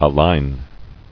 [a·lign]